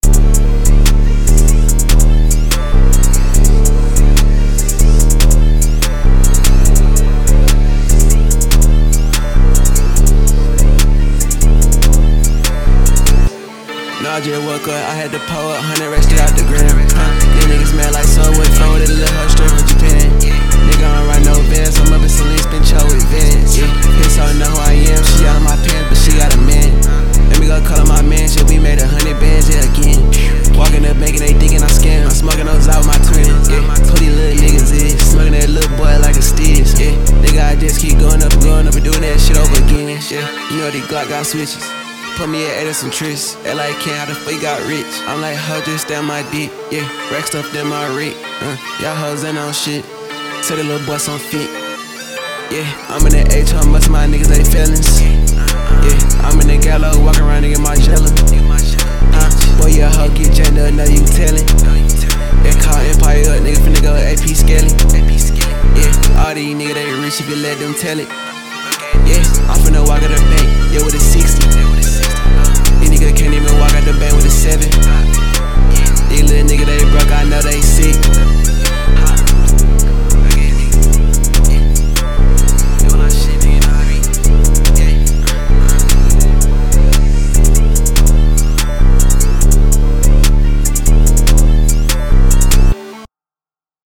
دانلود آهنگ سبک هیپ هاپ